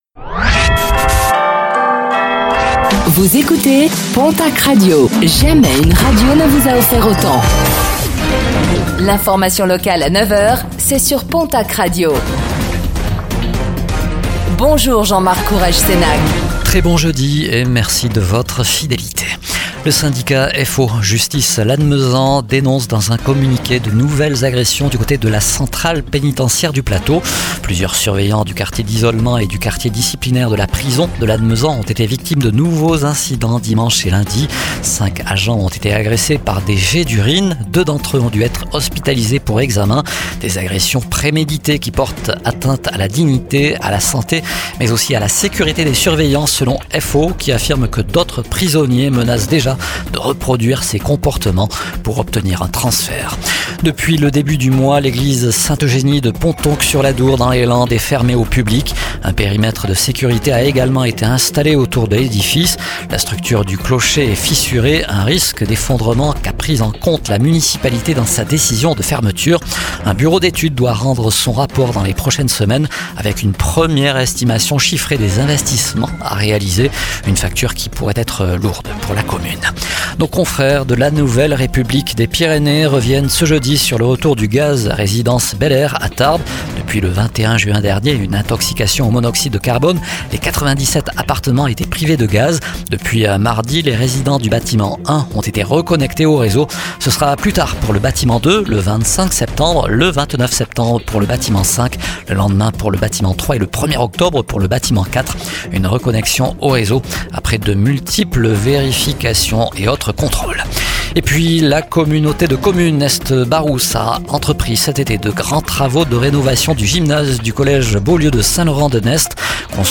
Réécoutez le flash d'information locale de ce jeudi 25 septembre 2025